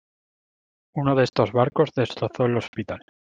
Read more Det Pron este Det Pron Intj Noun Read more Frequency A1 Hyphenated as es‧tos Pronounced as (IPA) /ˈestos/ Etymology Inherited from Latin istōs In summary From Latin istōs, accusative plural of iste.